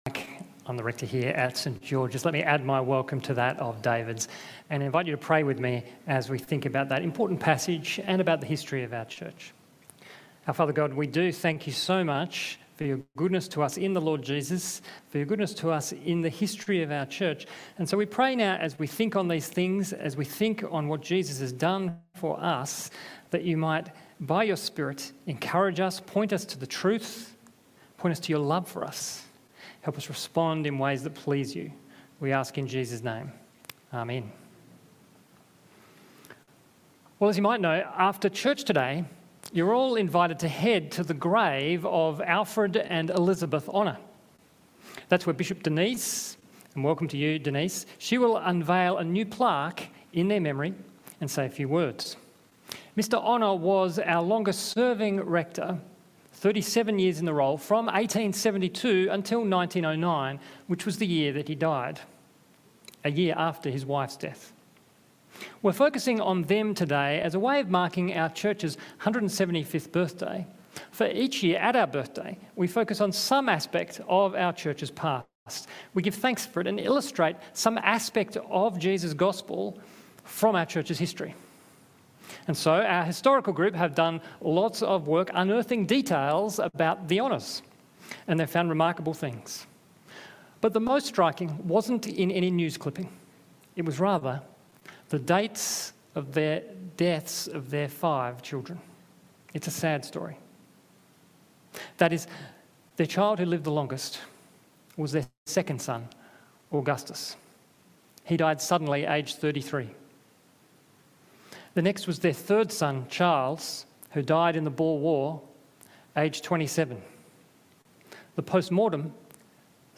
Sermons | St George's Magill Anglican Church